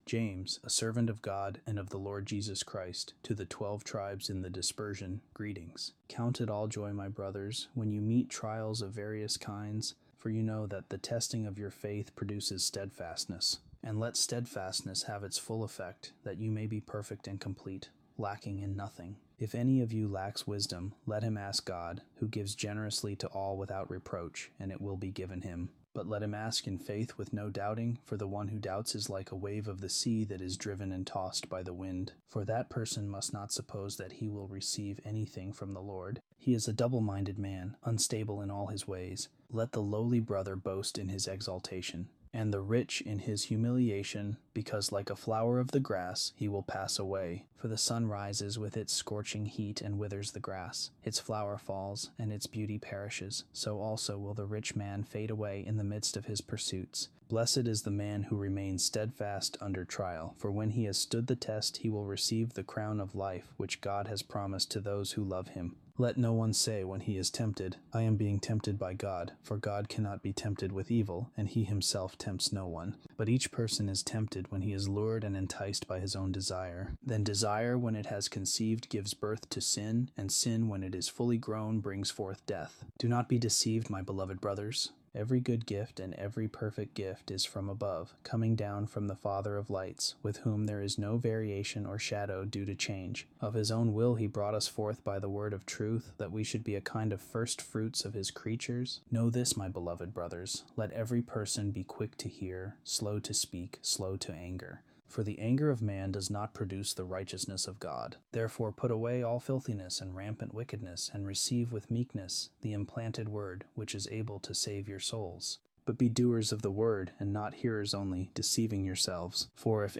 The sermon focuses on the relationship between hearing and doing the Word.